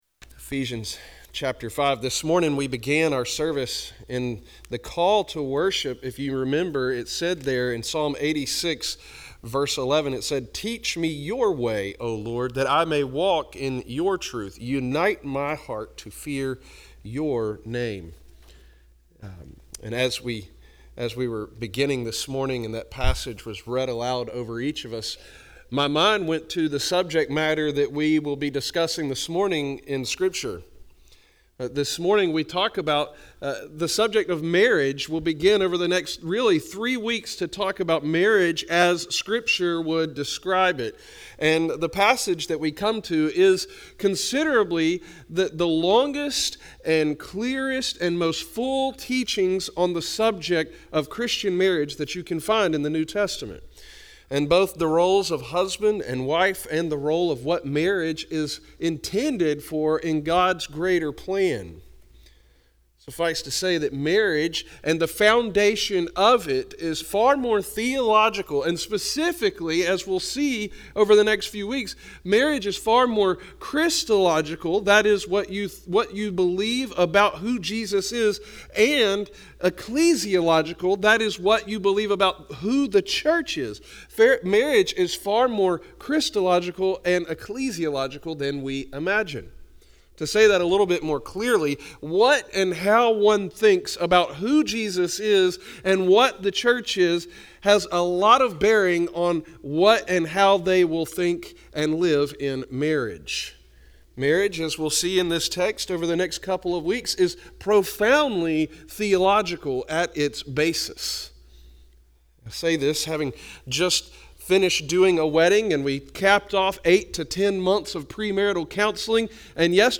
This message gives special focus to the command given to wives, namely, to submit to their own husbands.